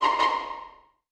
Suspicious_v4_wav.wav